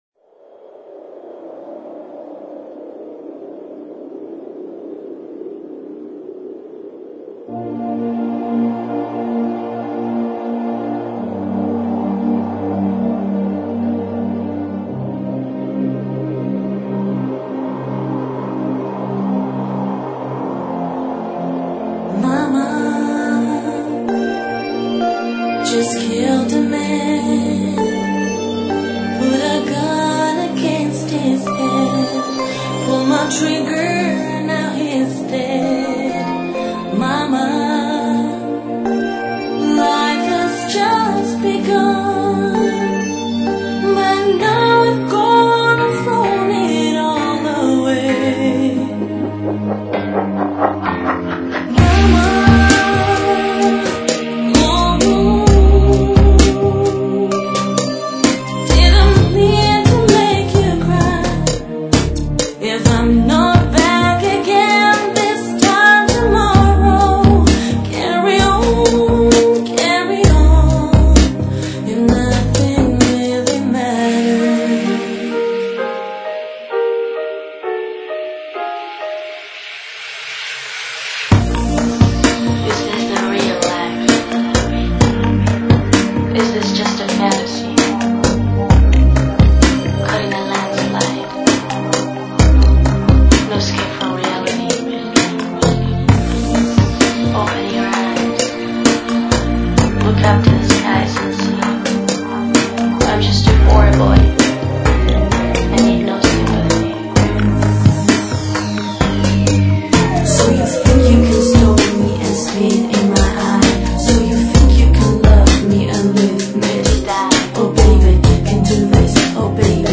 Chill Out